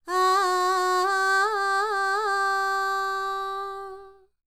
QAWALLI 04.wav